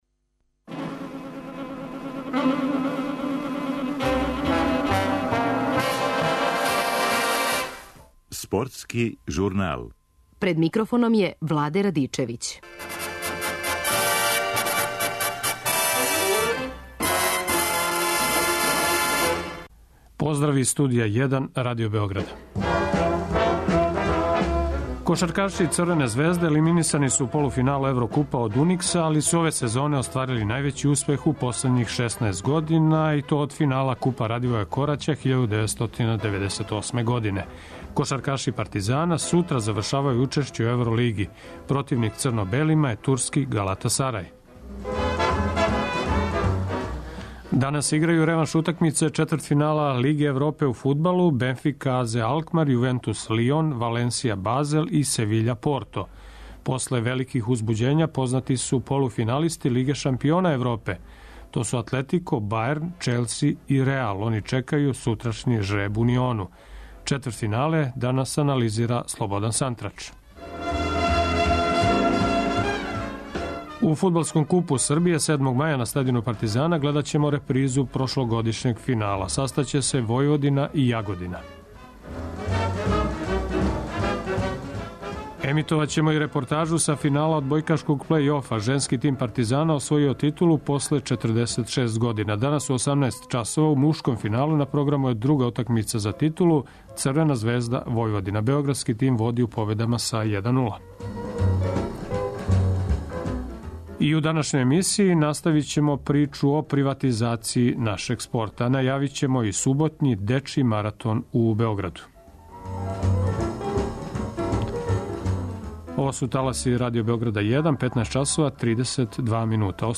Емитоваћемо и репортажу са финала одбојкашког плеј-офа.